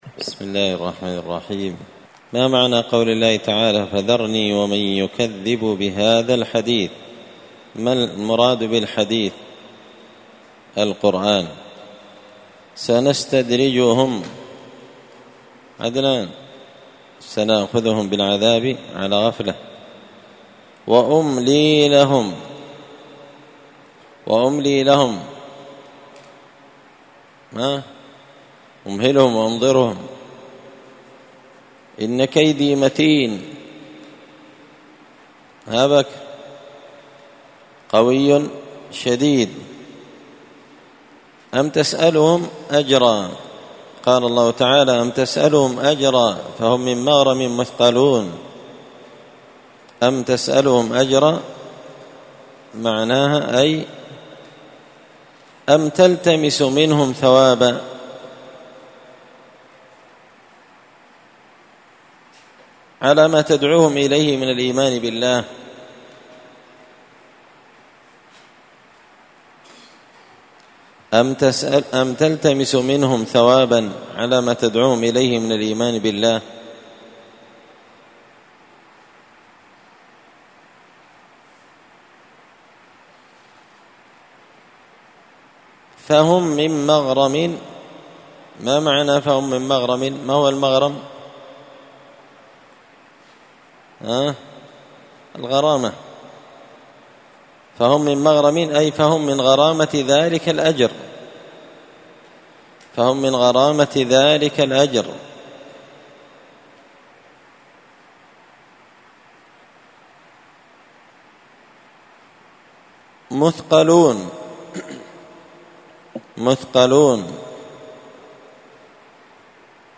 (جزء تبارك سورة القلم الدرس 67)